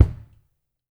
02A KICK  -R.wav